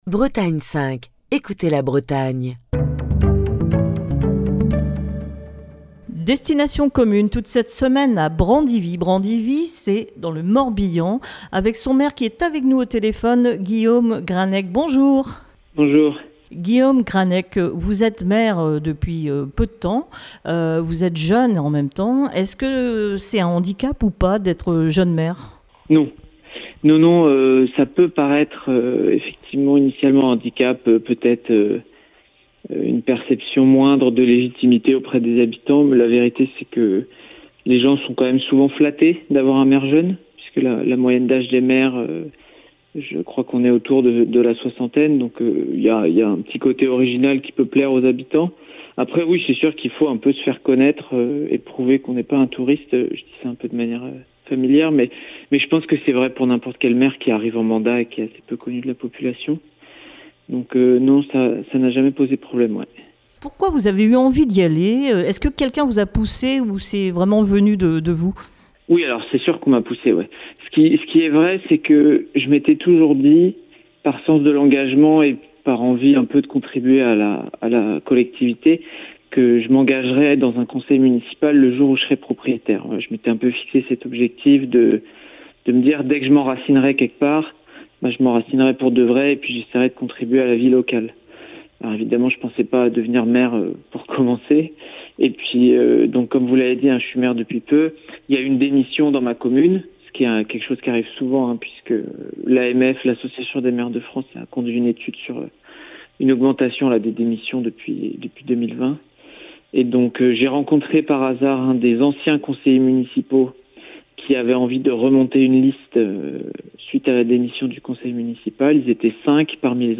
maire de Brandivy raconte sa commune